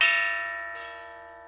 Category ⚡ Sound Effects
ding hit metal ring ting tone sound effect free sound royalty free Sound Effects